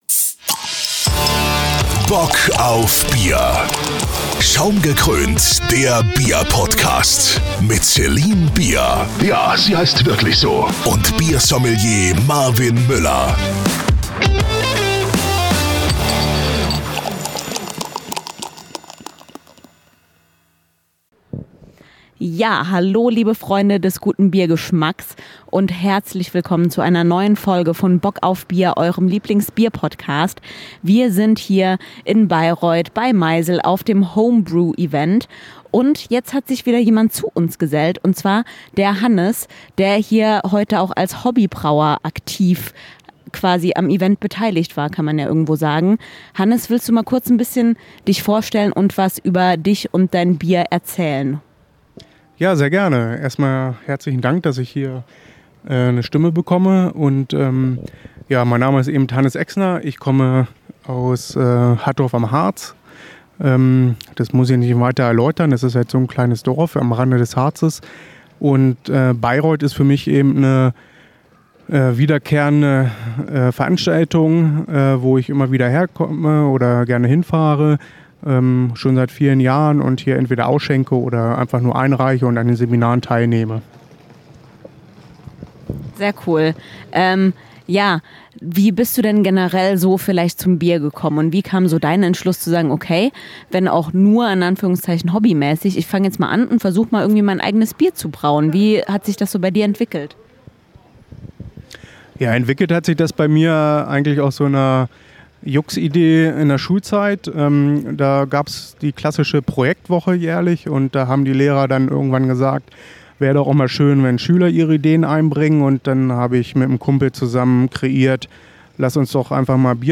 Bei unserem Besuch auf der Homebrew hatten wir die Gelegenheit mit ganz vielen Hobbybrauern zu sprechen, die uns mal ihre Sicht der Dinge erzählt haben.